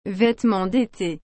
vêtements d’étéヴェトゥムォン デェテ